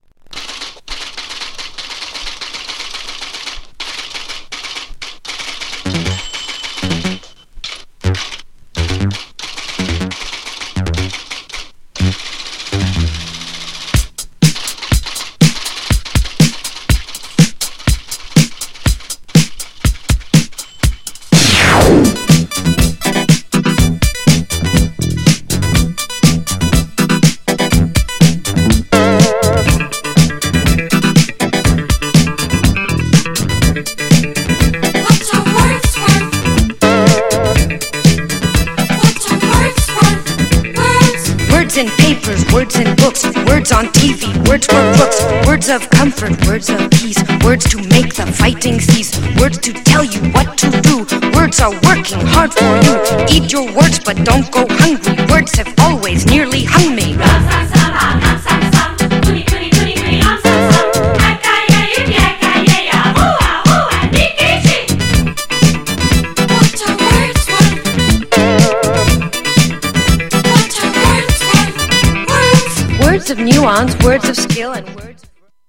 GENRE Dance Classic
BPM 121〜125BPM
# ダビー # ニューウェーブ